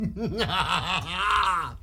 Wyld Evil Laugh Sound Buttons
wyld-evil-laugh.mp3